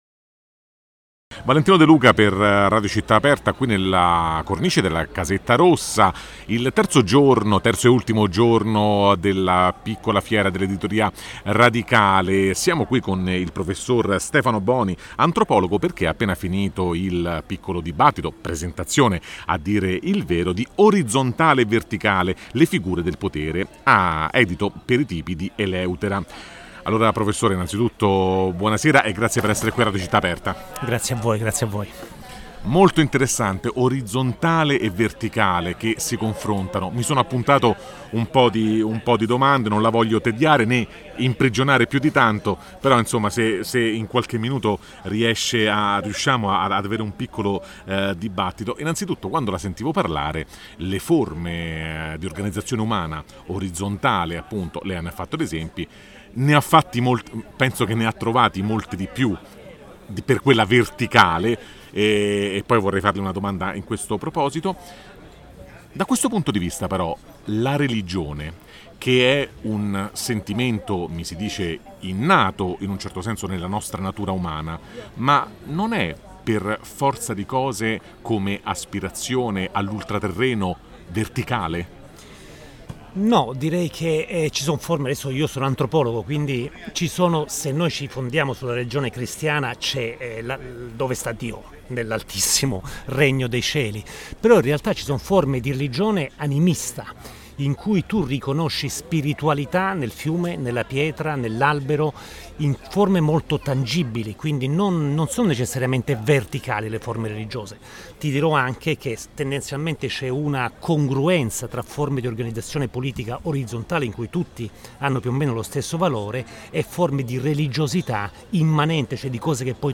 Radio Città Aperta è Media Partner della Piccola Fiera dell’Editoria Radicale!
Il 28 – 29 e 30 settembre 2021, nella sede di Casetta Rossa al quartiere Garbatella di Roma, si è tenuta un’importante manifestazione dell’editoria indipendente e “radicale”.